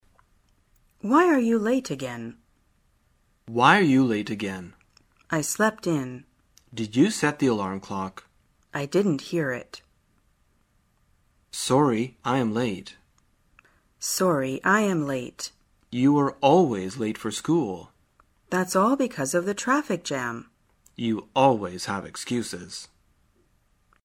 在线英语听力室生活口语天天说 第19期:怎样谈论迟到的听力文件下载,《生活口语天天说》栏目将日常生活中最常用到的口语句型进行收集和重点讲解。真人发音配字幕帮助英语爱好者们练习听力并进行口语跟读。